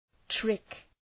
Προφορά
{trık}